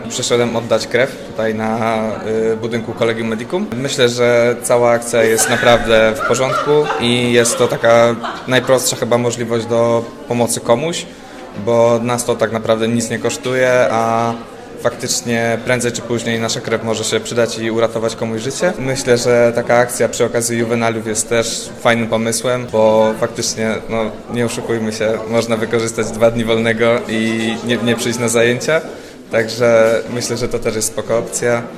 O tym mówił jeden z uczestników wydarzenia:
Uczestnik-wydarzenia-1.mp3